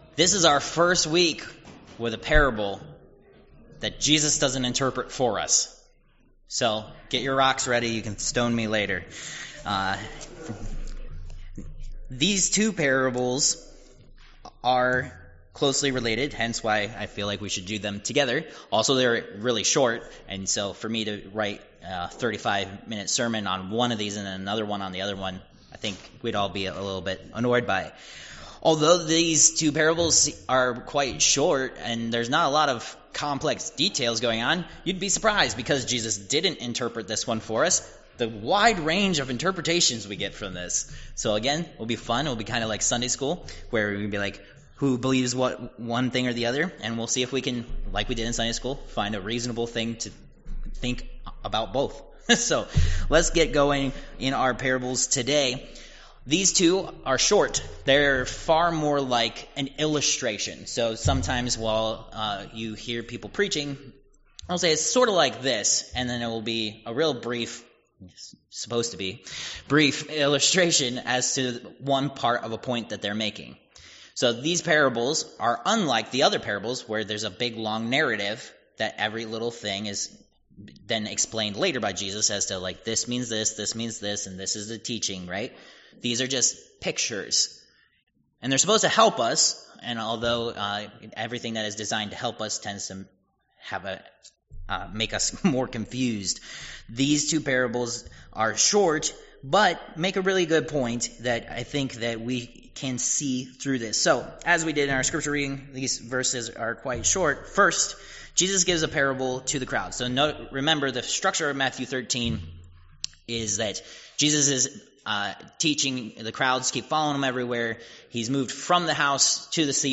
Matt. 13:31-35 Service Type: Worship Service « Protected